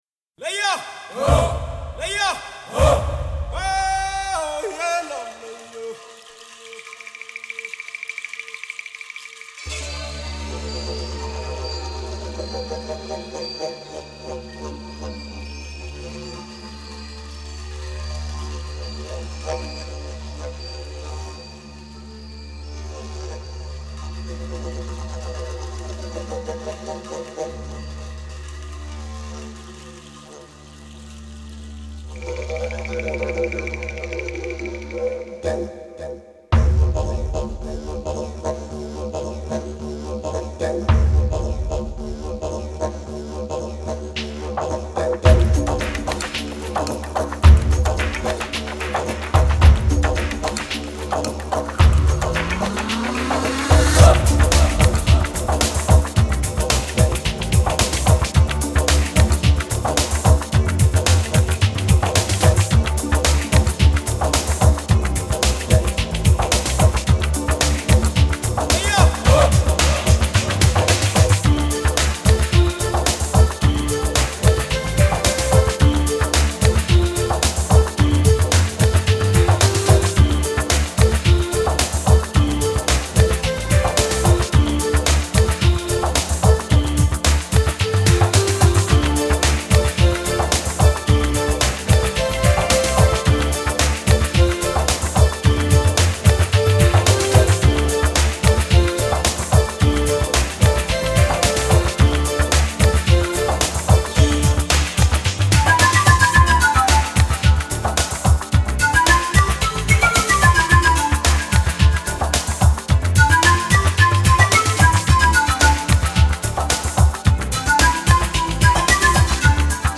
Нью эйдж New age